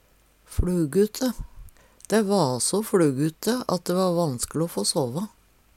fLugute - Numedalsmål (en-US)
DIALEKTORD PÅ NORMERT NORSK fLugute mange fluger på ein stad Eksempel på bruk Dæ va så fLugute at dæ va vanskLe o få såvå.